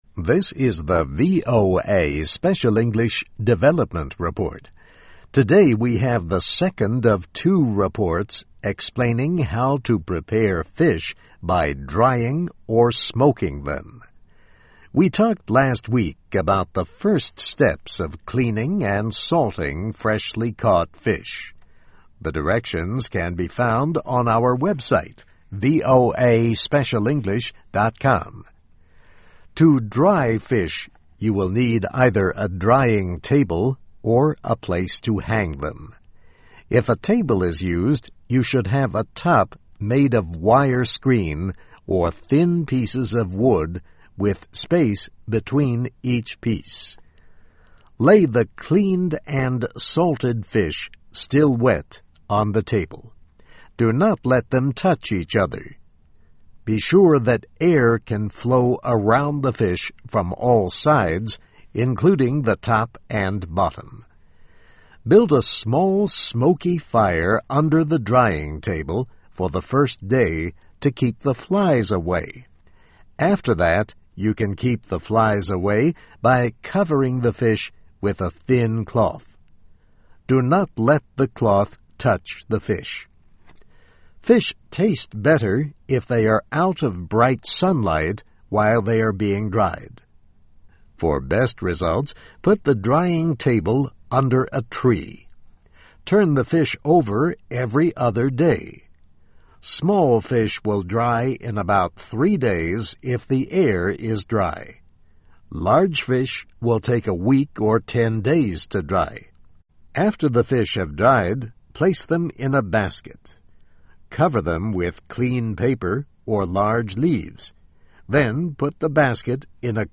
How: How to Dry or Smoke Fish, Part 2 (VOA Special English 2009-01-03)